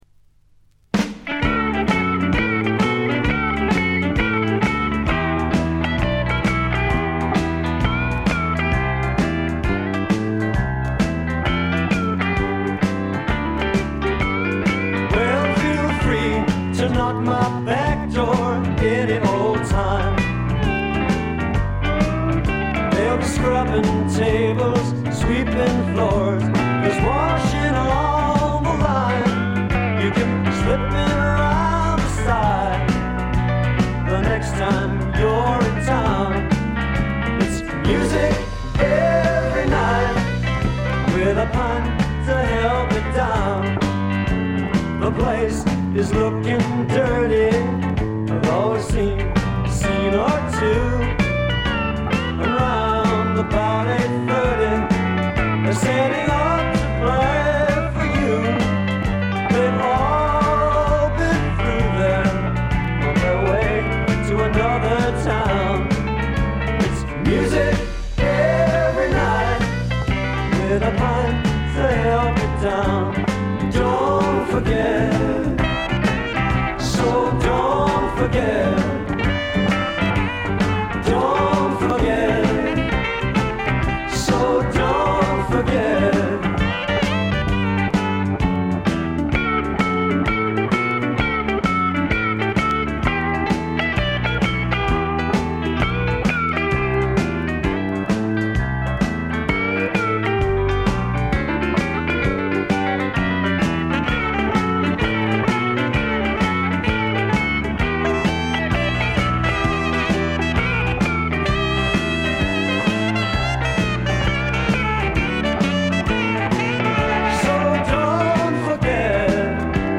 ほとんどノイズ感無し。
聴くたびにご機嫌なロックンロールに身をゆだねる幸せをつくずく感じてしまいますね。
試聴曲は現品からの取り込み音源です。